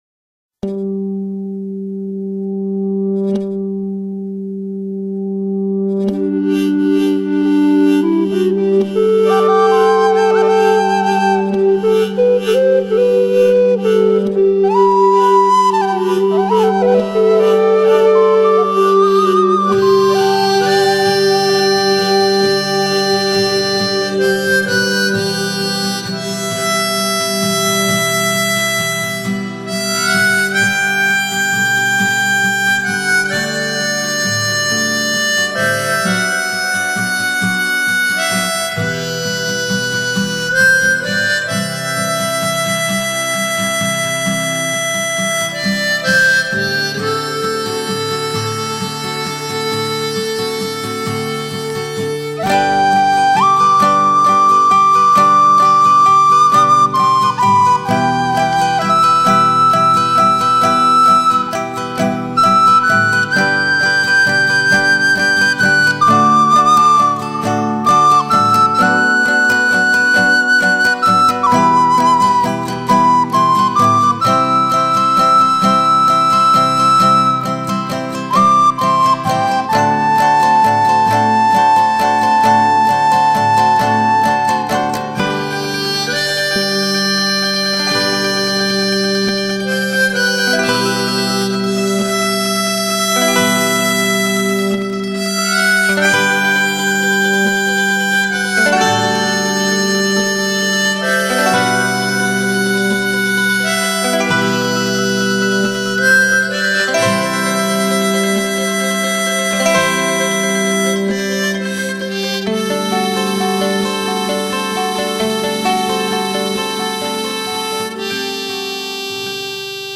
Songs Mp3 Bengali